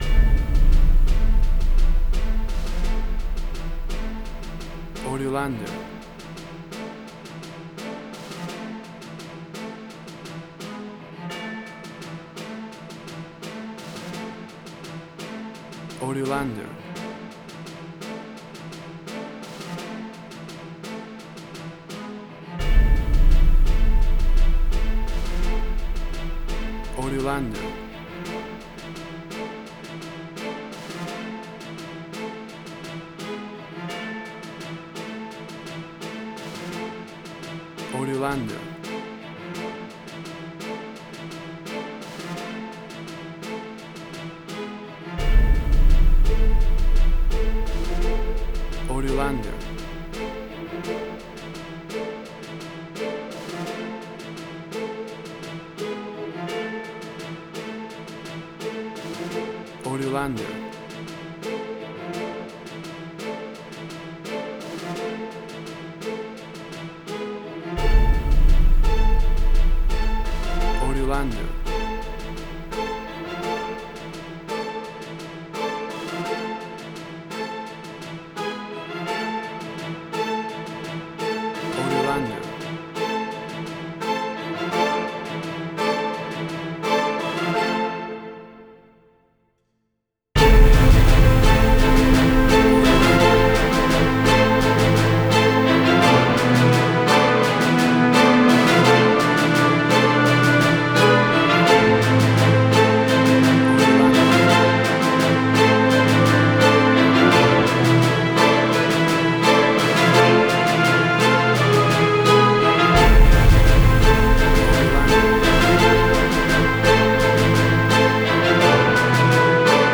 WAV Sample Rate: 16-Bit stereo, 44.1 kHz
Tempo (BPM): 124